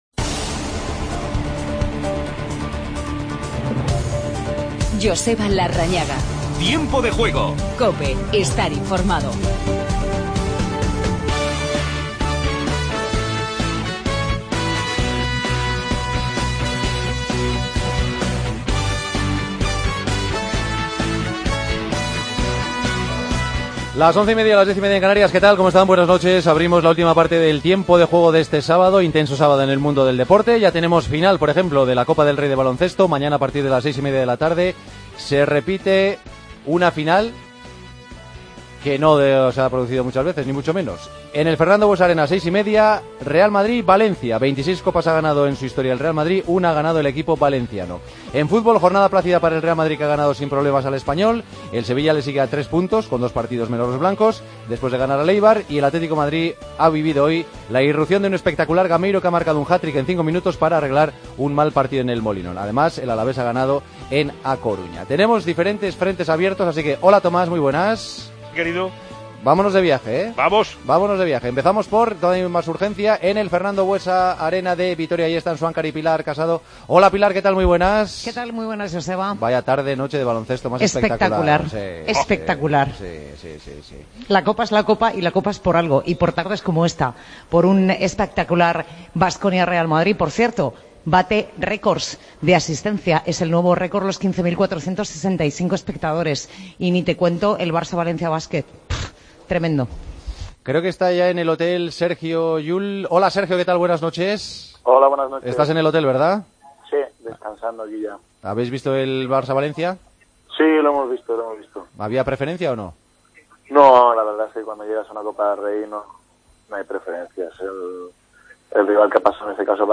Hablamos con Sergi Llull, Rafa Martínez y Sarabia.